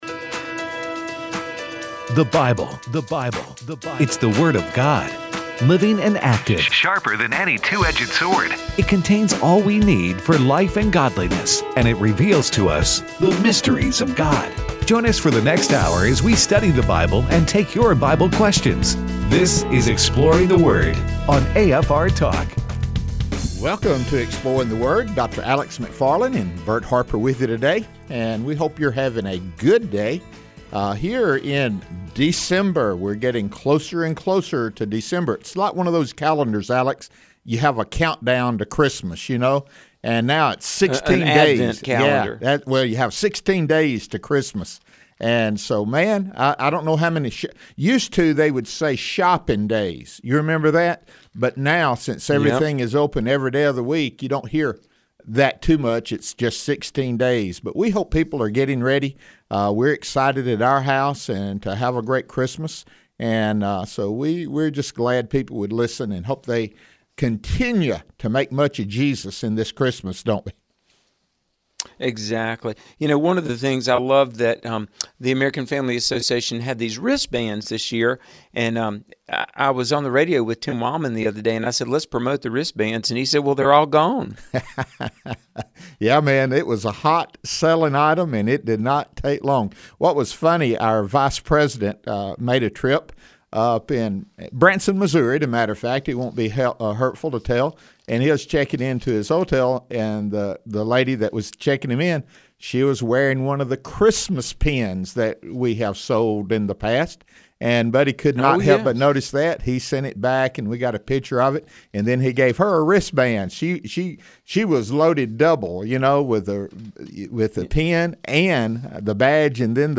They also take your phone calls.